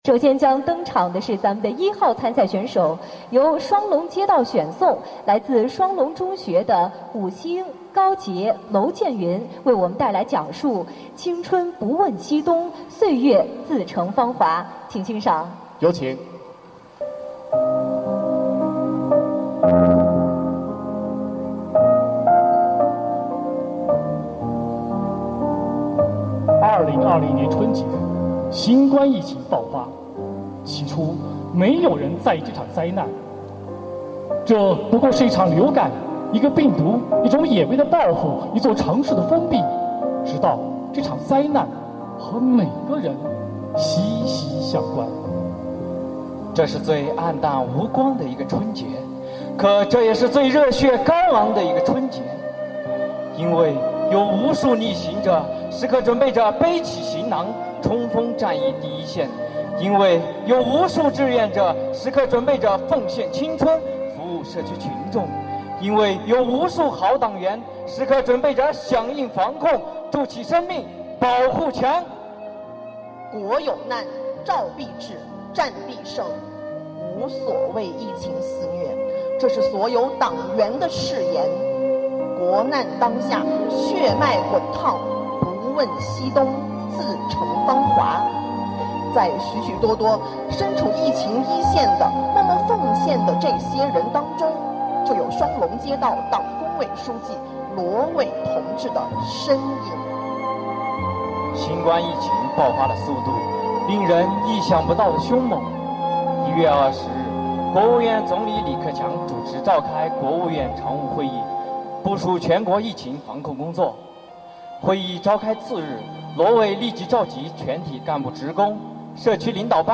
发布于 2020-09-12 09:59 分类: "书香盘龙"全民阅读活动 2020年6月—8月，以“坚定文化自信 弘扬中华美德 践行社会主义核心价值观”为主题的2020年“书香盘龙”全民阅读系列活动——第三届“盘龙江畔话好人”讲故事比赛，历经了社区及街道选拔赛并于8月28日完美收官。
为把每一个“好人故事”讲给所有人听，我们专门为每一个参赛选手录制了音频，每天我馆都将在微信平台为您展播一位优秀选手带来的好人故事。